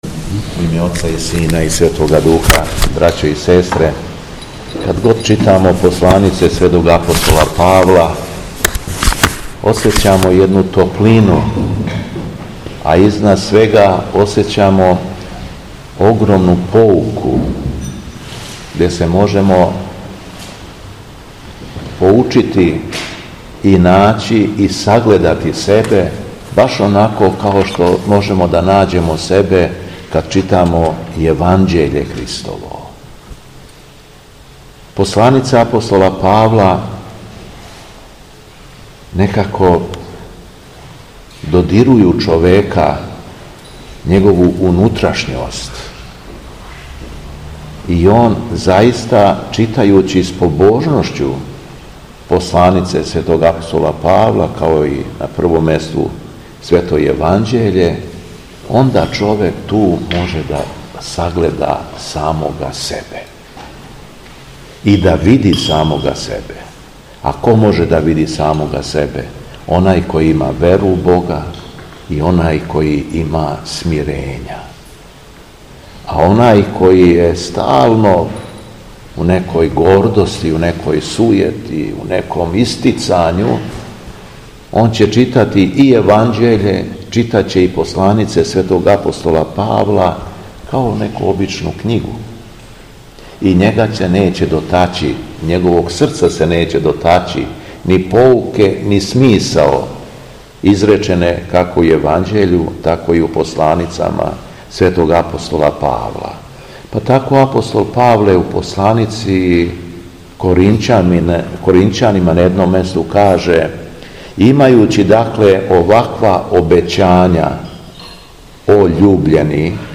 Беседа Његовог Високопреосвештенства Митрополита шумадијског г. Јована
Након прочитаног зачала Светог јеванђеља, архијереј се обратим поучном беседом између осталог рекавши: